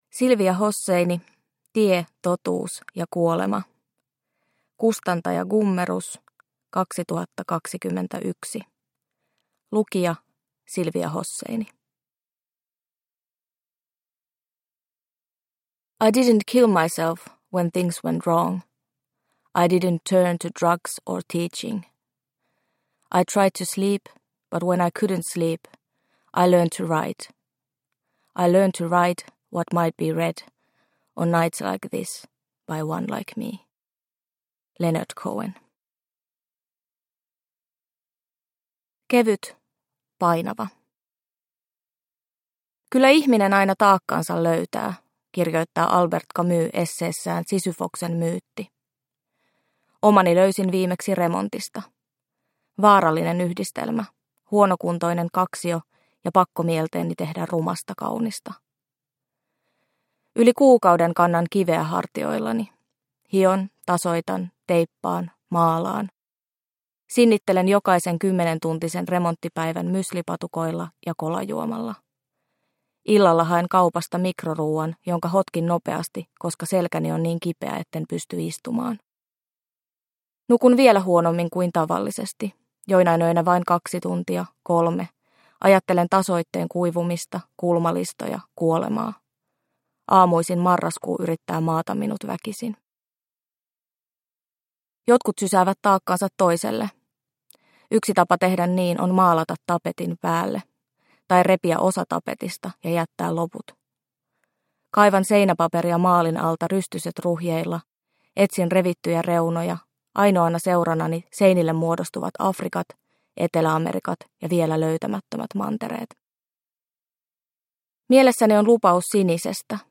Tie, totuus ja kuolema – Ljudbok – Laddas ner